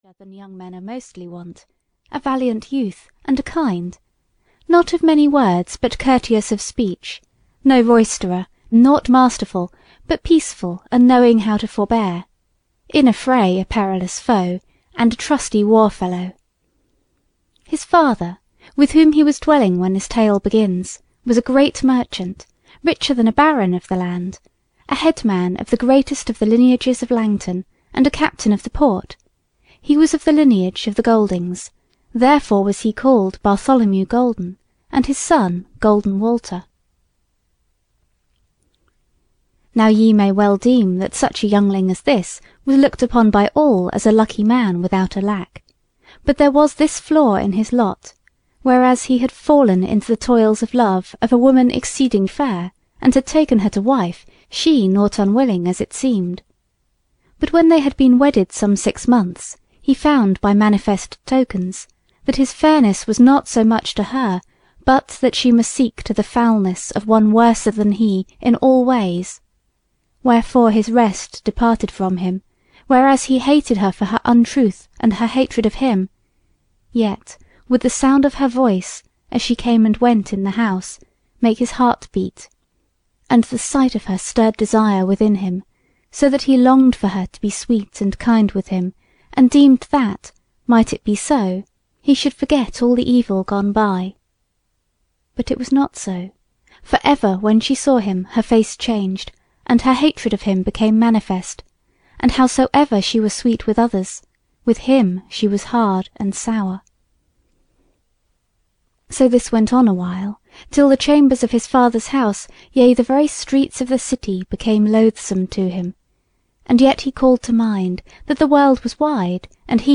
Audio knihaThe Wood Beyond the World (EN)
Ukázka z knihy